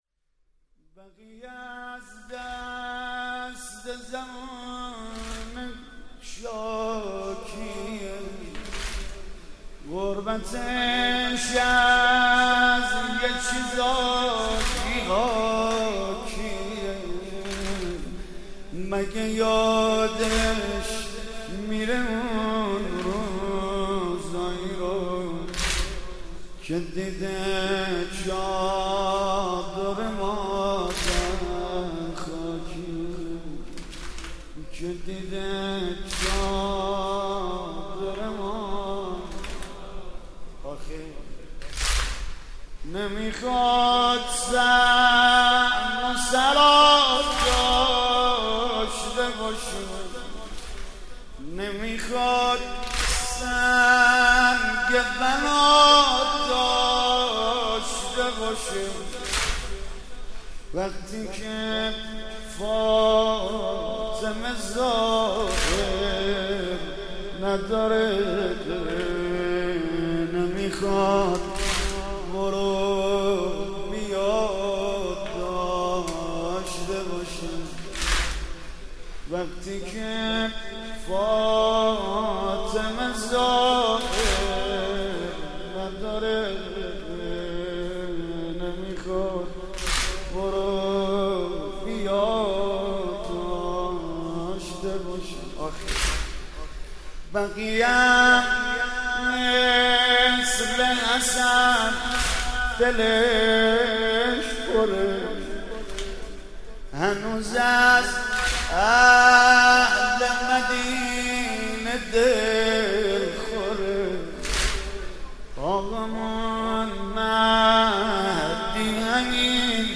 «ویژه مناسبت تخریب بقیع» واحد: بقیع از دست زمونه شاکیه